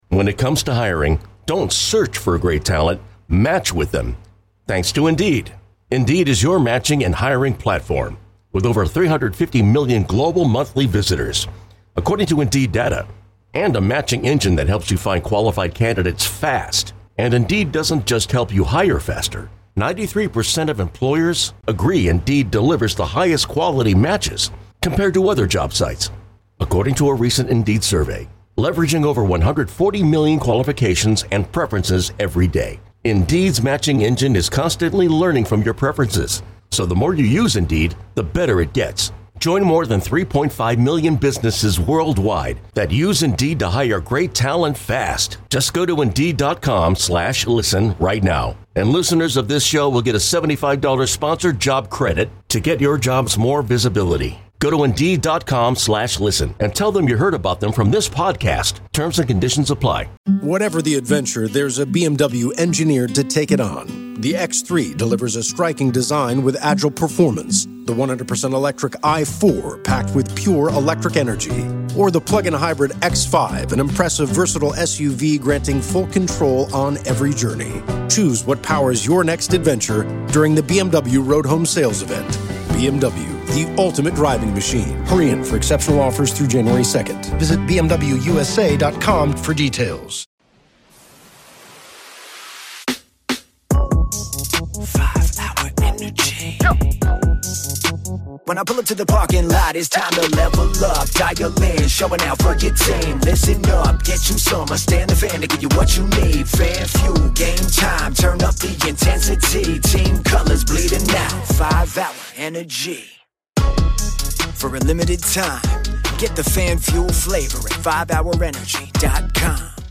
live from the Jack Buck Awards